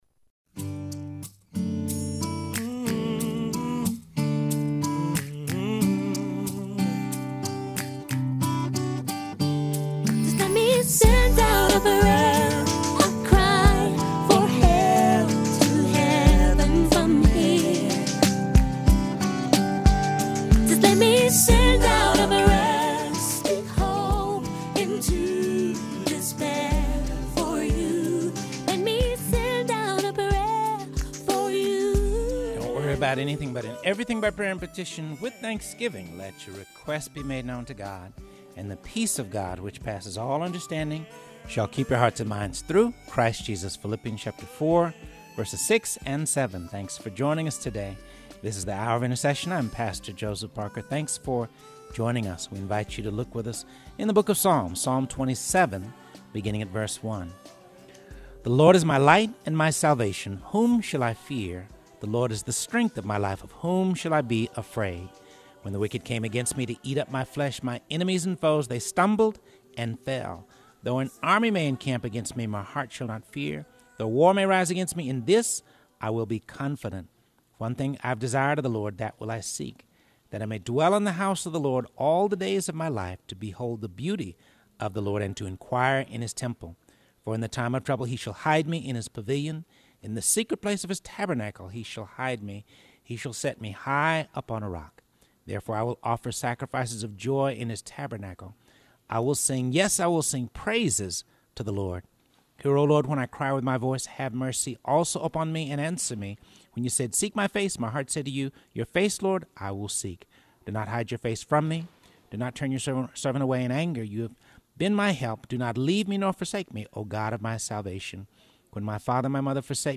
broadcasts LIVE from The Heart Cry for Revival Fusion Conference Day 3